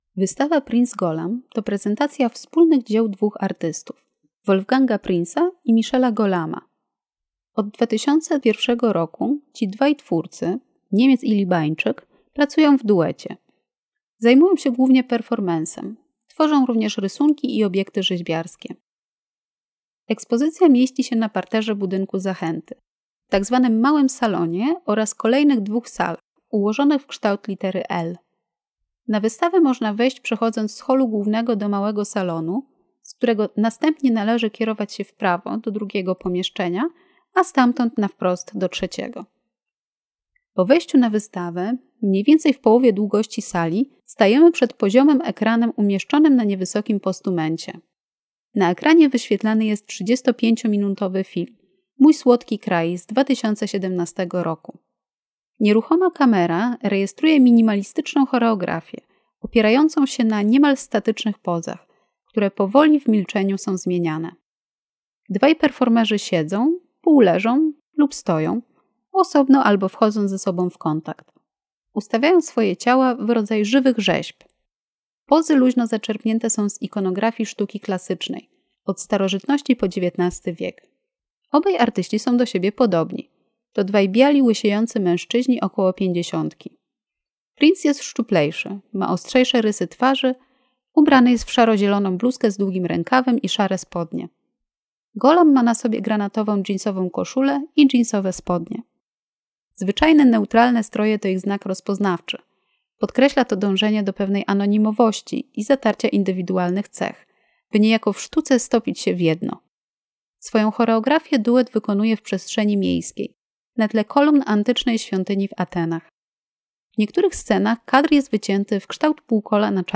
Audiodeskrypcja wystawy: Prinz Gholam - Mediateka - Zachęta Narodowa Galeria Sztuki